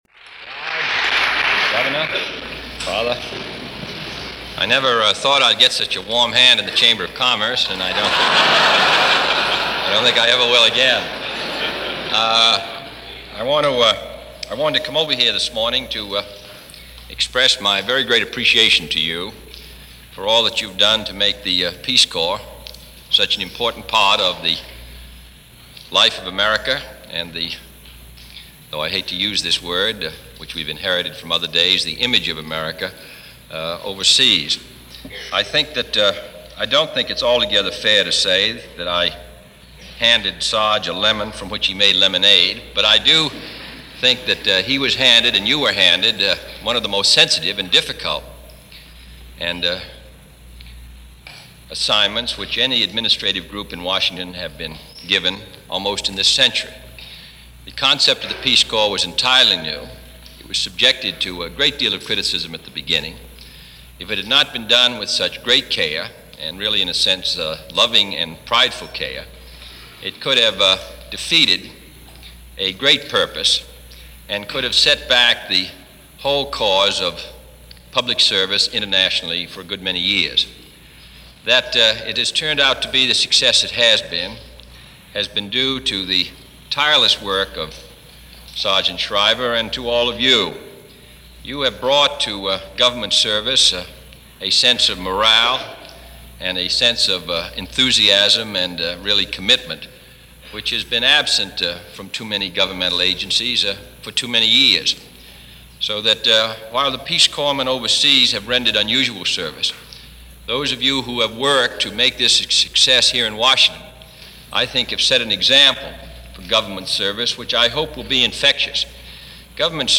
June 14, 1962 - JFK and The Peace Corps - Introduction Address and Question and answer period regarding The Peace Corps - Washington D.C,.